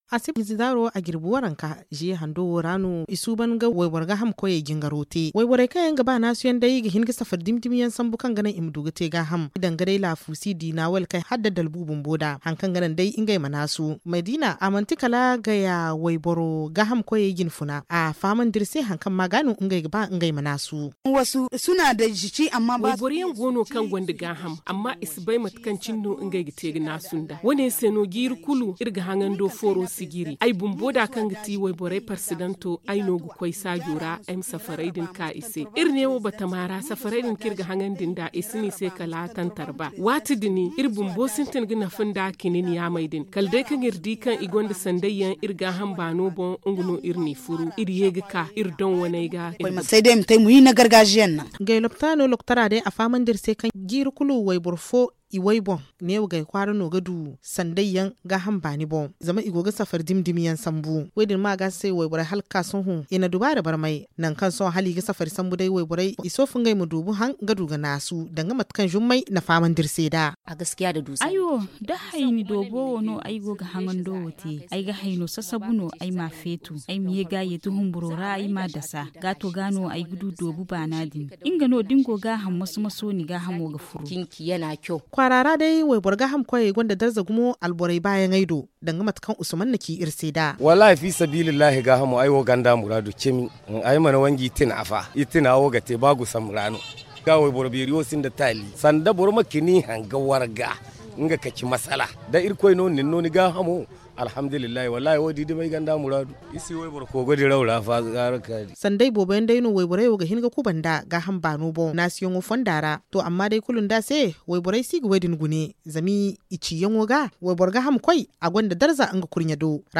A Gaya, par exemple, chaque année est organisée une fête pour désigner la plus ronde des femmes. Reportage